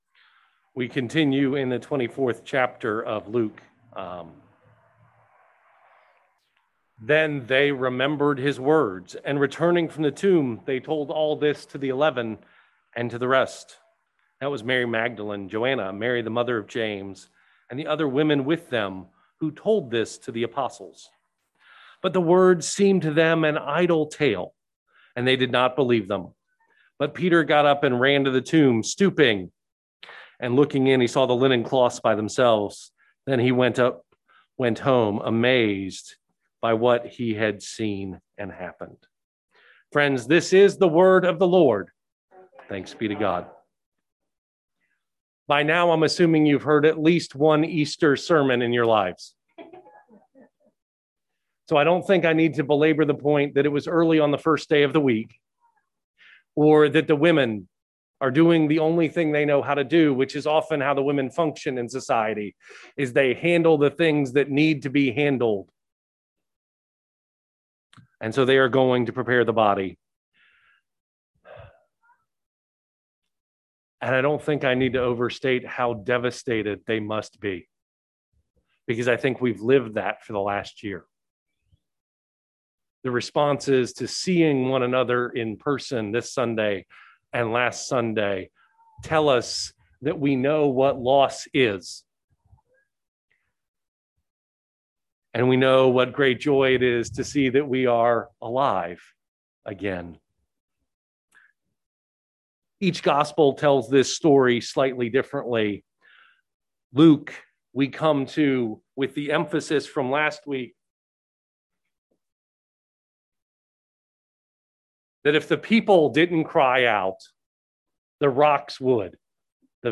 April 4, 2021 – Remember his Words – Easter Sunday – First Presbyterian Church of Marion, Illinois
Worship 2021